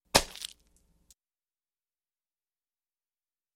Звук раздавленного помидора ногой